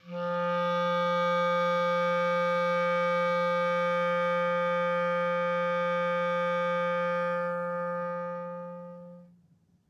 Clarinet
DCClar_susLong_F2_v3_rr1_sum.wav